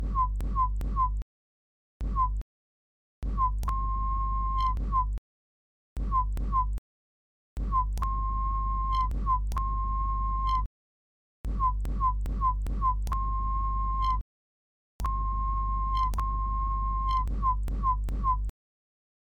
Соберите морзянку. А еще, весь этот свист не просто так.
Свистом по морзянке собираем СЕРИЯ 47.
Мне действительно пришлось выпячивать губы, когда я записывал свист для позапрошлого уровня) Дакфейс